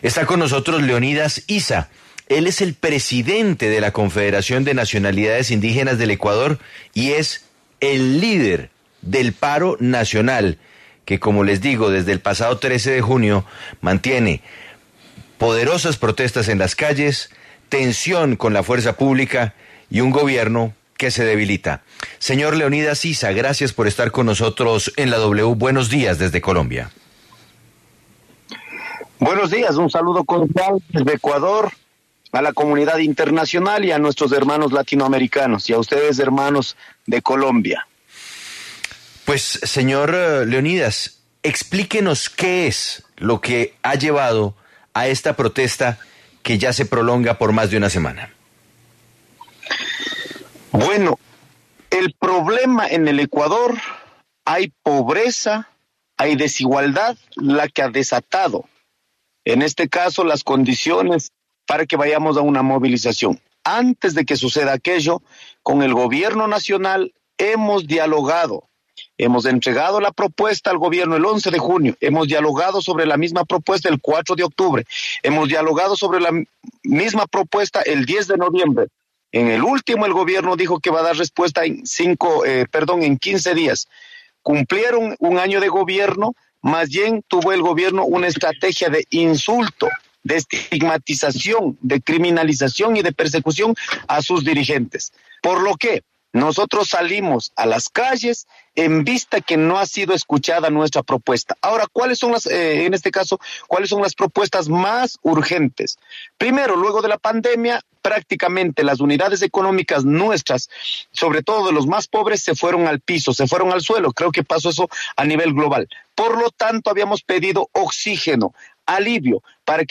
Leonidas Iza, líder del paro nacional en Ecuador, habló en La W sobre las peticiones que hacen los ciudadanos al Gobierno de presidente Guillermo Lasso.